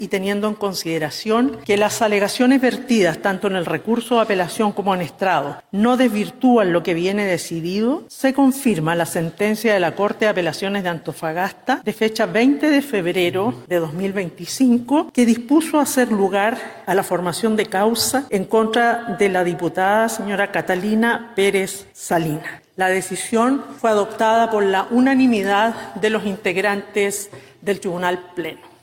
Al respecto, la ministra vocera de la Suprema, María Soledad Melo, dio lectura al fallo, el cual fue visado por la unanimidad de sus miembros.